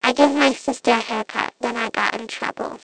Звук слухового аппарата для детей с нарушениями слуха